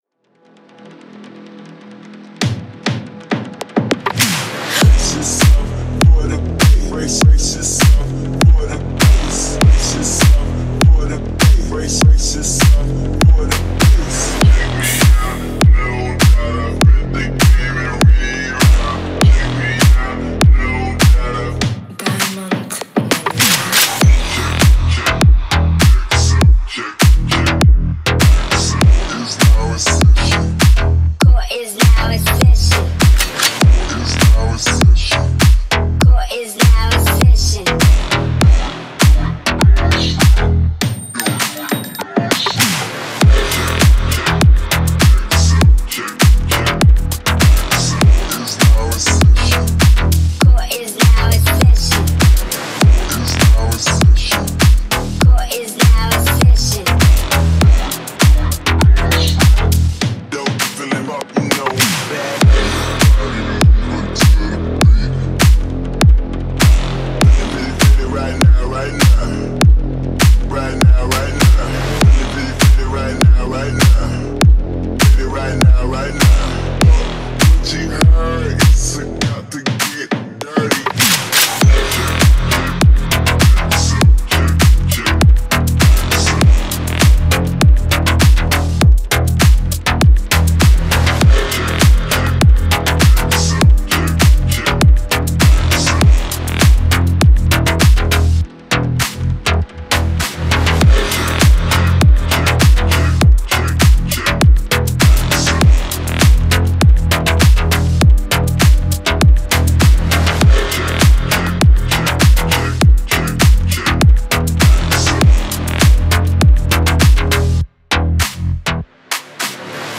Клубная музыка
злой клубняк